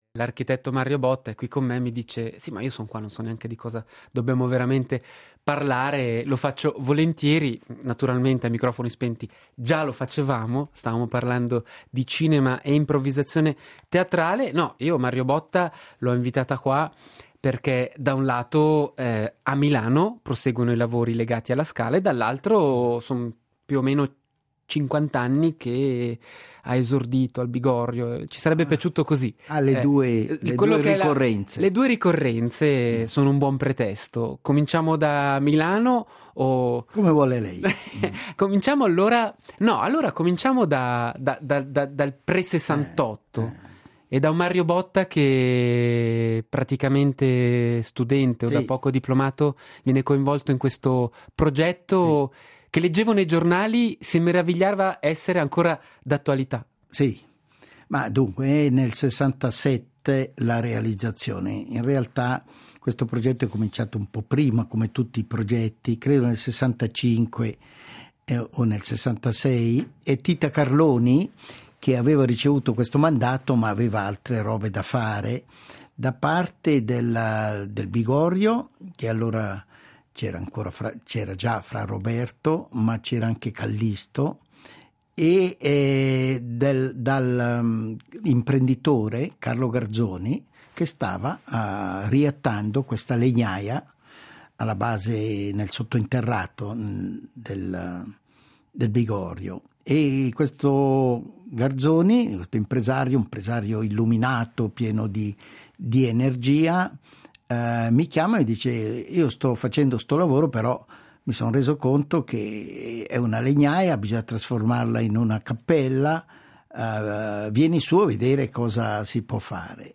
Intervista a Mario Botta